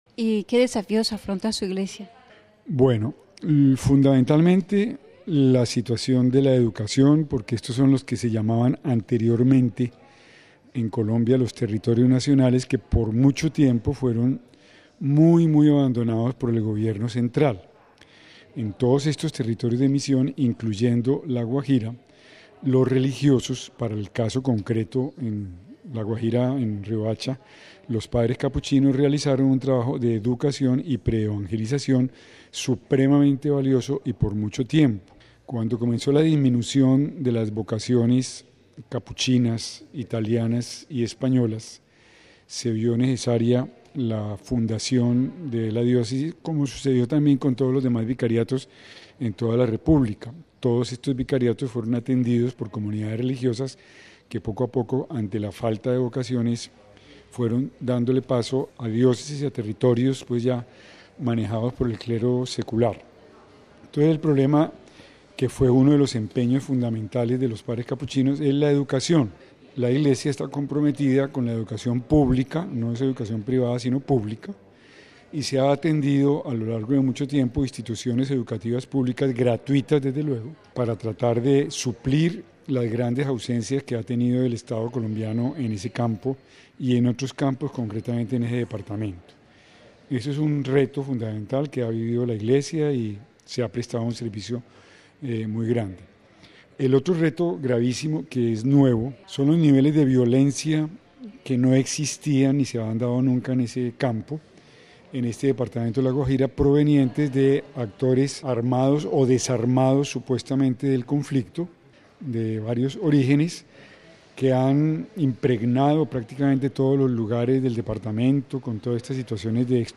(RV):.- En nuestras entrevistas a los obispos colombianos, en Roma en su visita ad limina, con nosotros el obispo de Riohacha, Mons. Héctor Salah quien nos habla en primer lugar sobre la realidad de su diócesis que en estos días cumple 25 años de fundación luego de más de 100 años de servicio y misión que realizaron los capuchinos.